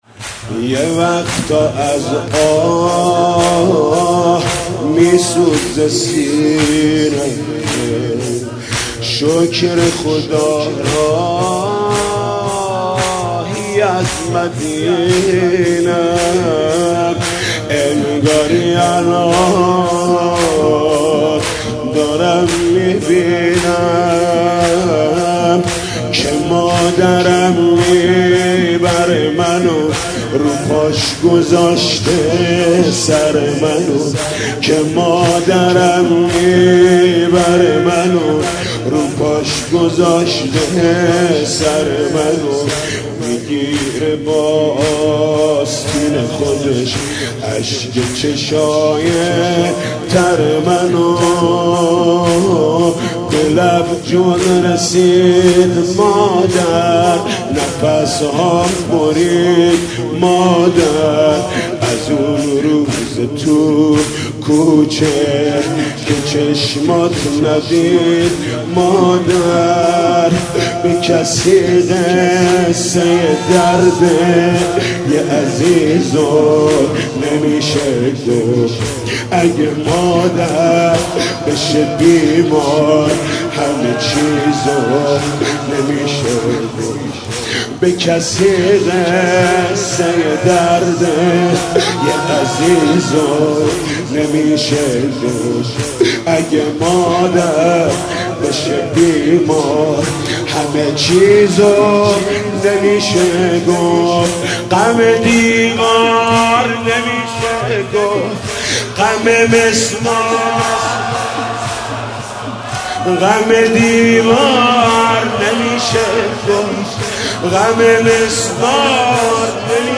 «فاطمیه 1396» زمینه: به کسی قصه ی دردِ یه عزیزو نمیشه گفت